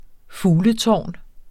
Udtale [ ˈfuːlə- ]